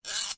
拨浪鼓的种类 Bt Hs Lg
描述：拨浪鼓金属铿锵声
Tag: 金属 拨浪鼓